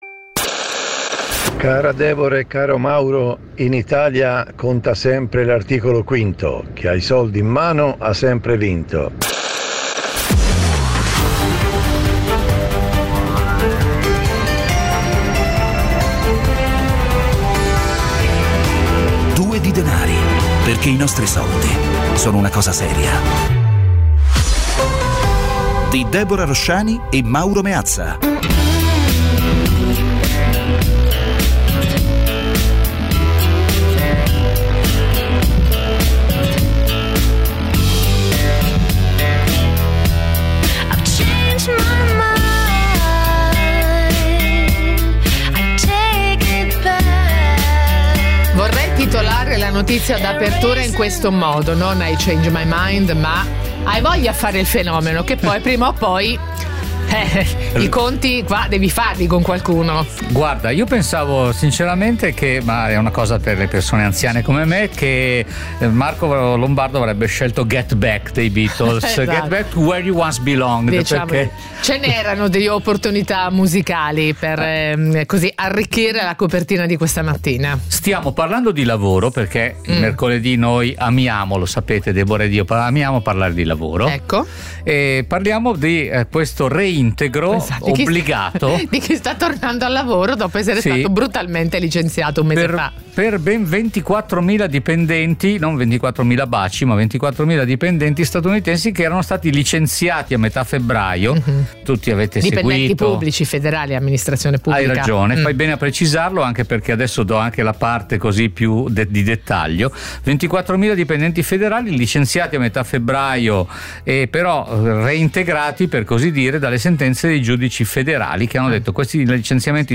Ogni giorno, su Radio 24, in questo spazio vengono affrontati con l'aiuto degli ospiti più competenti, uno sguardo costante all'attualità e i microfoni aperti agli ascoltatori.
La cifra, da sempre, è quella dell’ "autorevoleggerezza" : un linguaggio chiaro e diretto, alla portata di tutti.